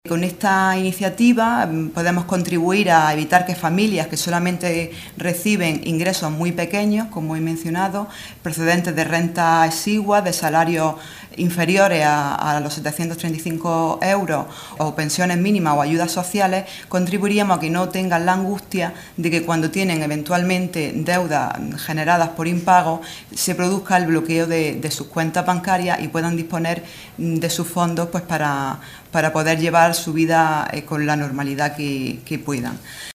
Rueda de prensa que han ofrecido las diputadas provinciales del PSOE de Almería, Ángeles Castillo y Anabel Mateos